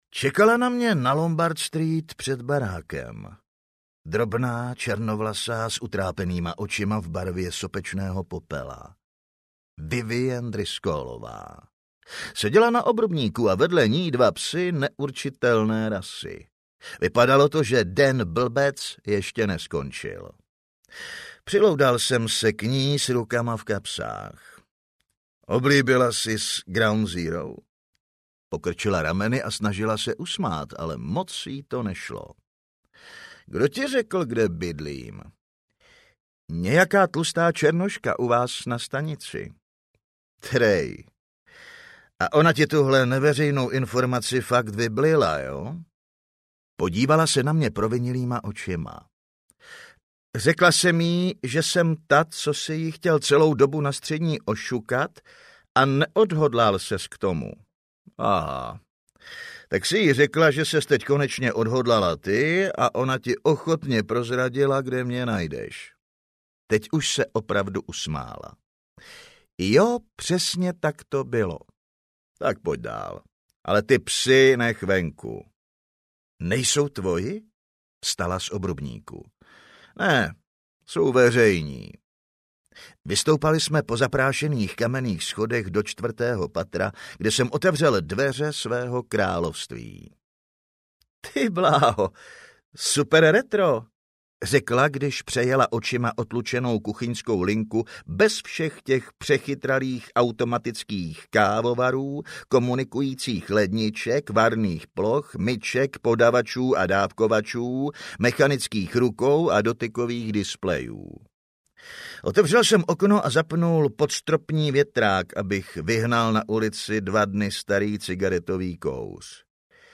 Yarminův syndrom audiokniha
Ukázka z knihy